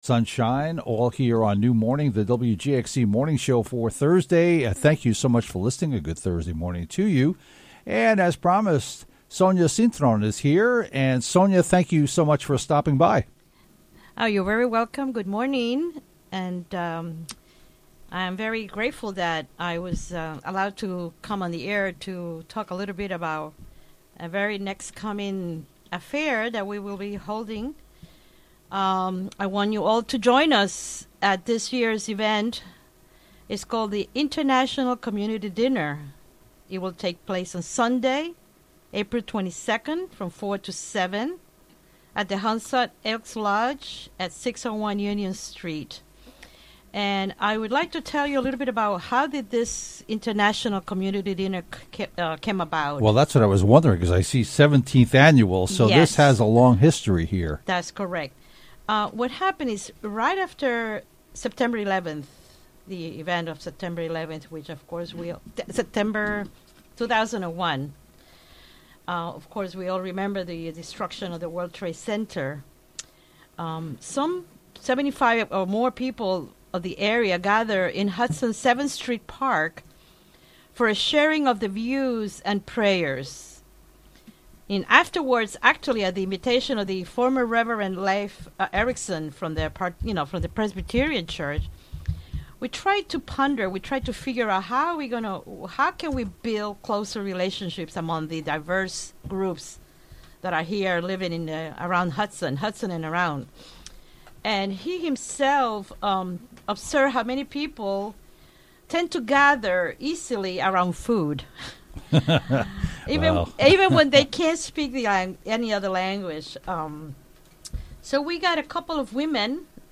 Recorded live on the WGXC Morning Show, Apr. 12, 2018.